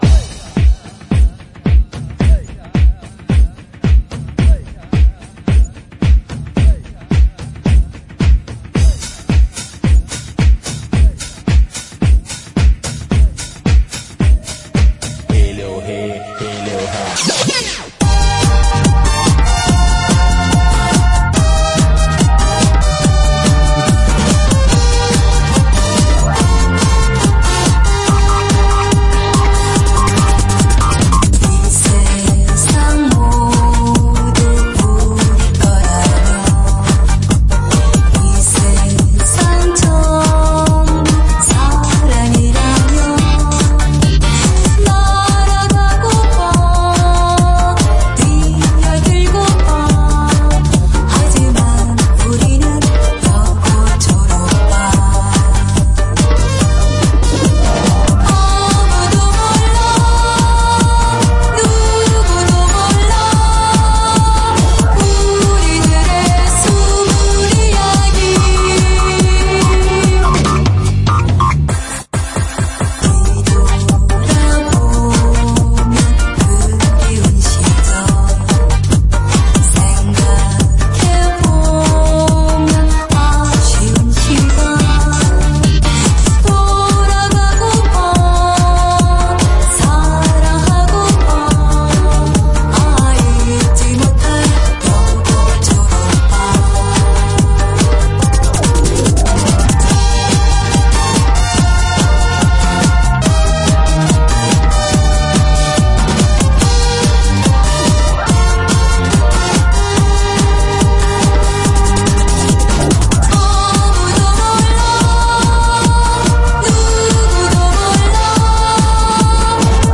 Swing Ver 듣기